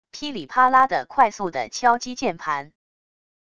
噼里啪啦的快速的敲击键盘wav音频